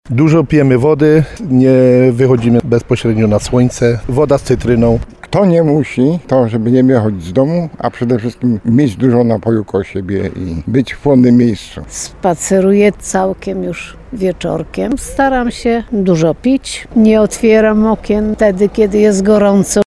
Zapytaliśmy mieszkańców, jak radzą sobie z falą gorąca.